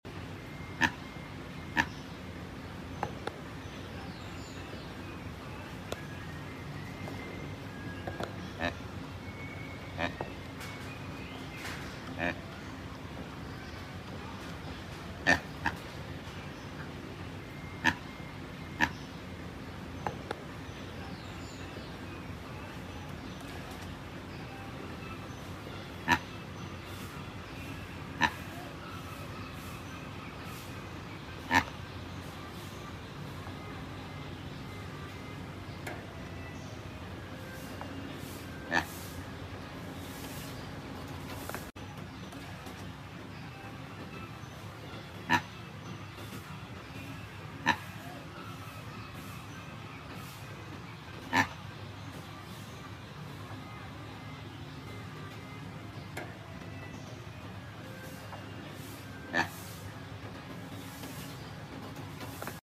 Proboscis Monkey Amazing video.